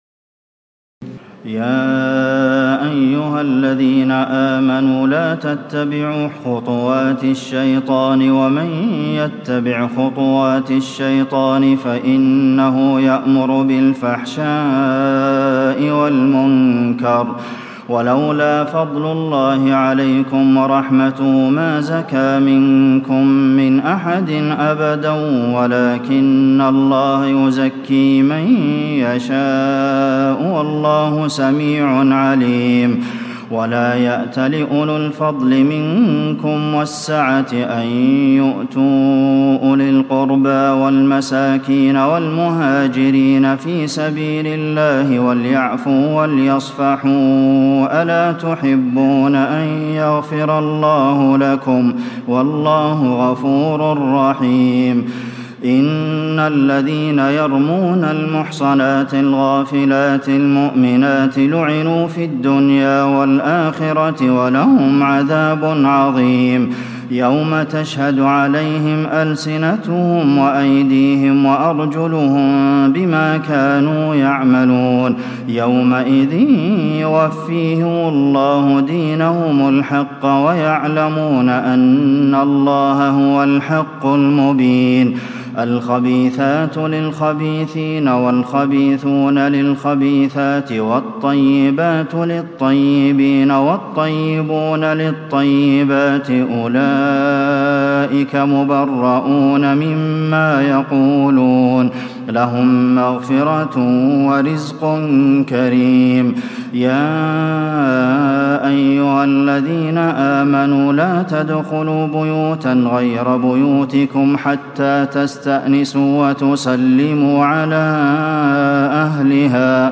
تراويح الليلة السابعة عشر رمضان 1436هـ من سورتي النور (21-64) و الفرقان (1-20) Taraweeh 17 st night Ramadan 1436H from Surah An-Noor and Al-Furqaan > تراويح الحرم النبوي عام 1436 🕌 > التراويح - تلاوات الحرمين